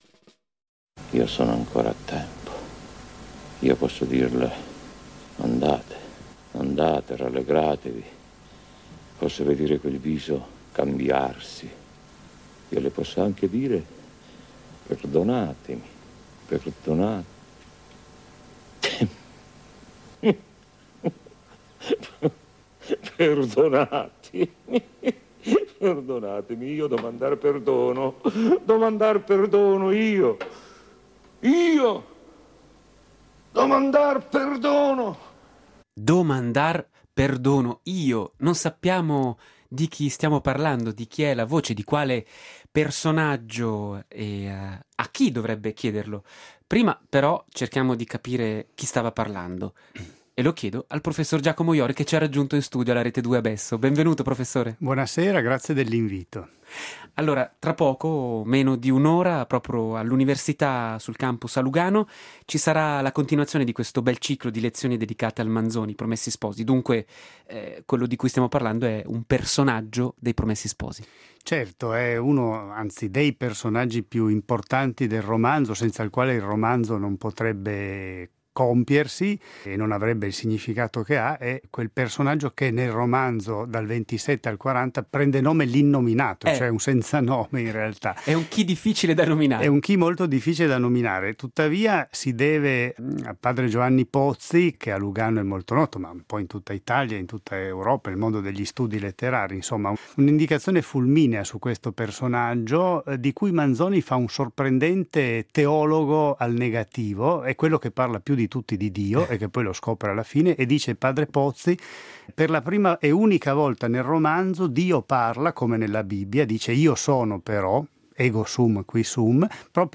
lezione manzoniana